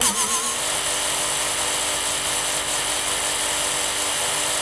rr3-assets/files/.depot/audio/sfx/transmission_whine/trans_on_low.wav